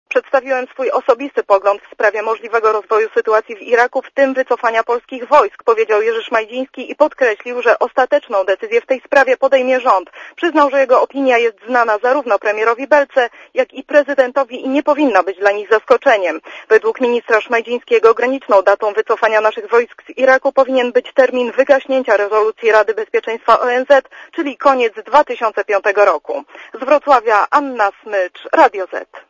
O sytuacji w Iraku rozmawiamy stale, bo jest to najtrudniejsza misja polskich żołnierzy - powiedział podczas konferencji prasowej we Wrocławiu Szmajdziński.